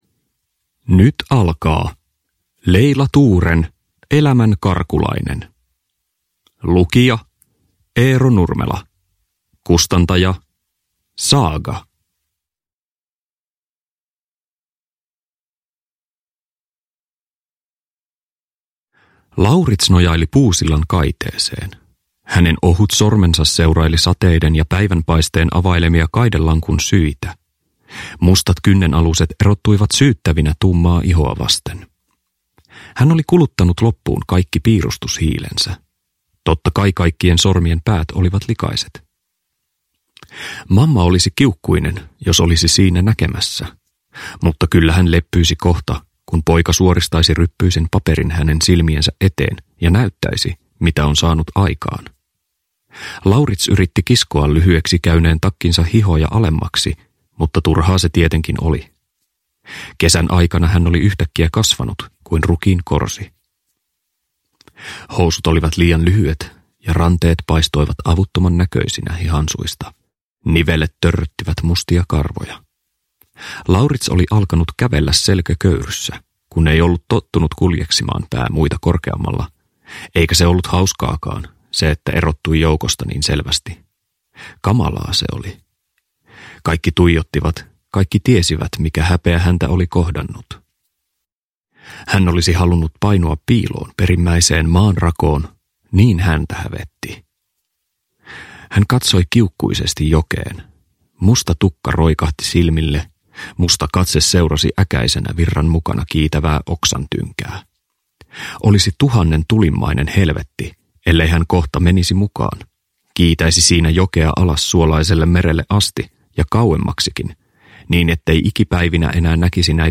Elämänkarkulainen (ljudbok